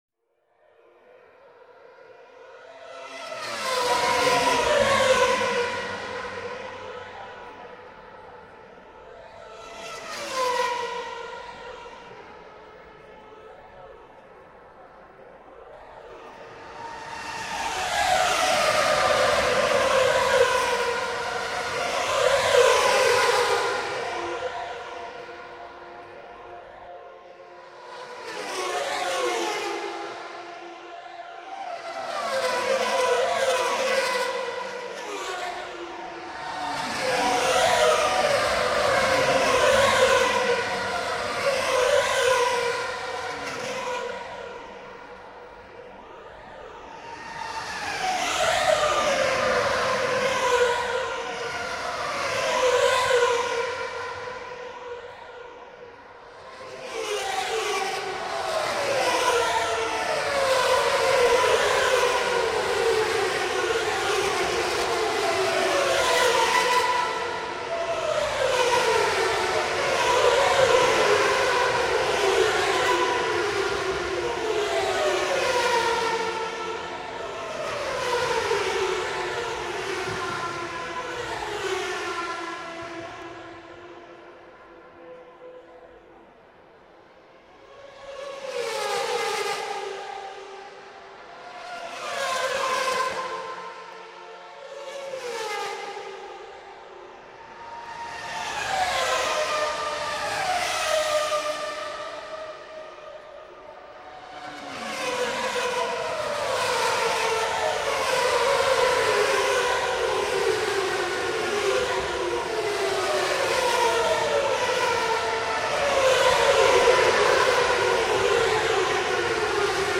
Passaggio di vetture: Quello che i commissari di pista subiscono per 2 ore, ogni 2 domeniche.
passaggio_vetture.mp3